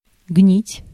Ääntäminen
US GenAM: IPA : [ɹɑt]